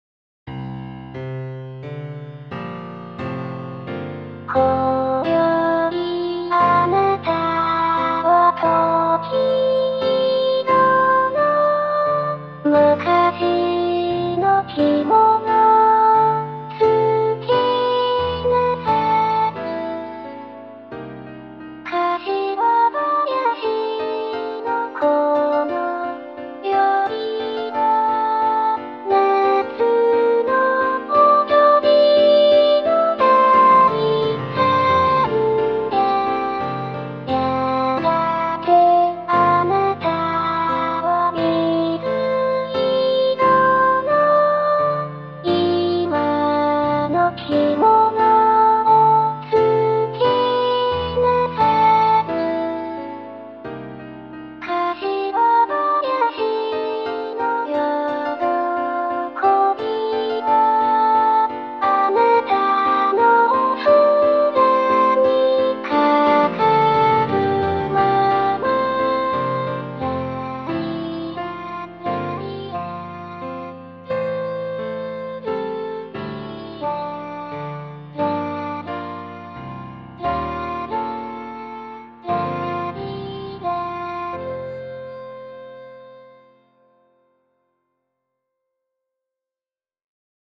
全体的に速すぎないように、ゆったり歌って下さい。
UTAUというFreeのソフトを使って、初めてボーカロイドを作ってみました。
ボーカロイドの音源(声)は、クラッシック向きではないですね。
それほどおかしくないような気もします。(子供の声みたいですけれど）